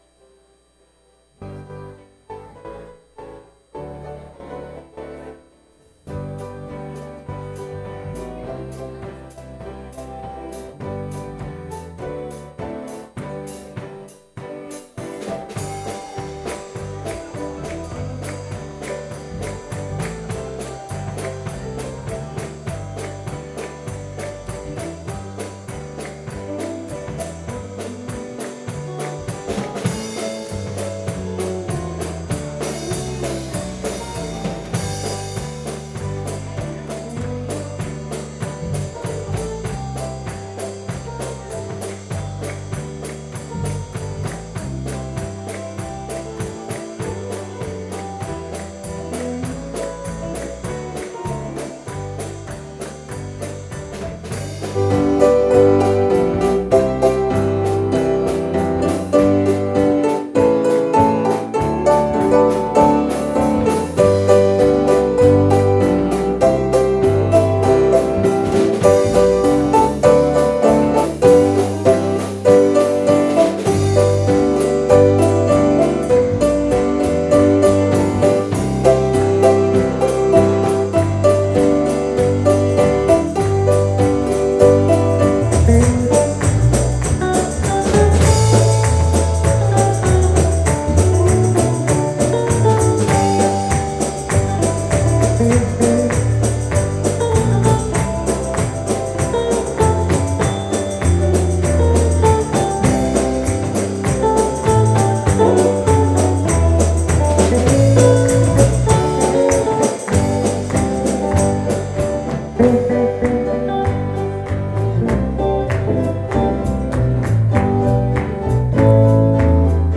Singing Service